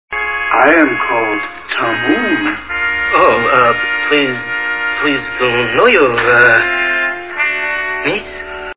Chekov sounds scared!!!